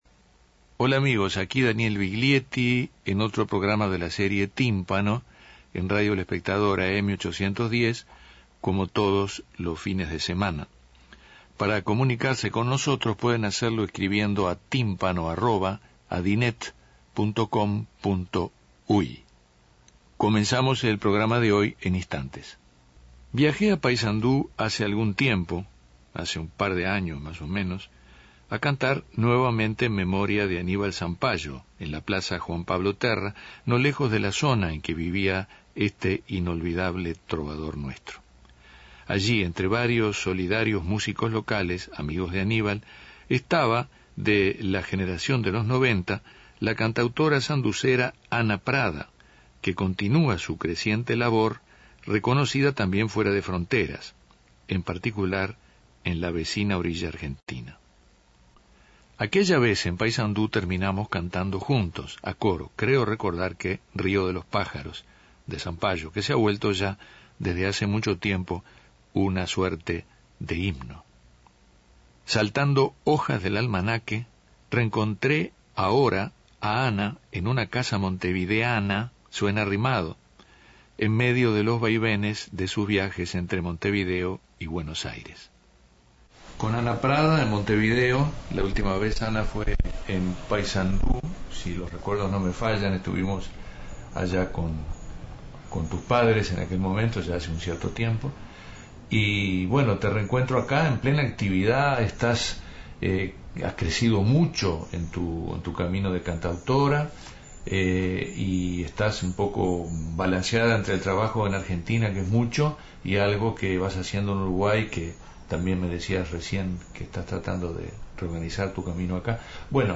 Escuche la entrevista con la cantautora sanducera Ana Prada en Tímpano